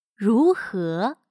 如何/Rúhé/Cómo, qué; como, que. Que tal si…